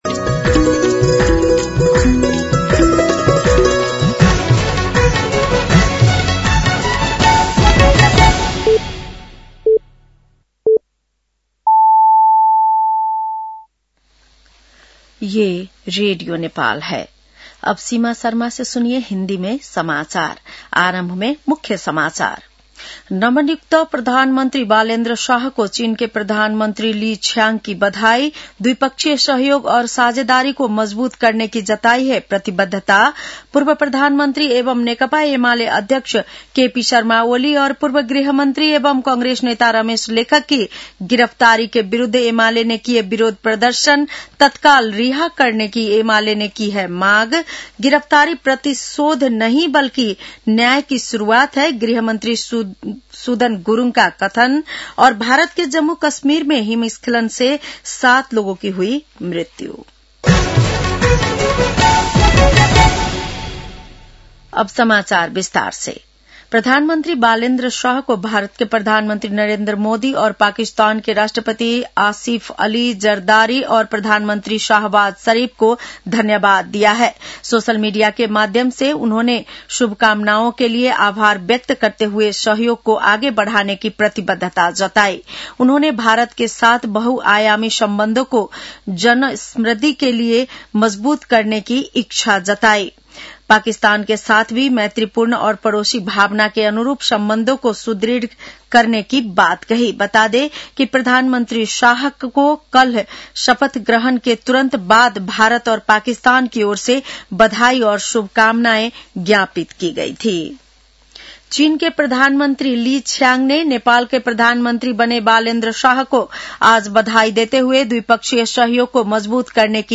बेलुकी १० बजेको हिन्दी समाचार : १४ चैत , २०८२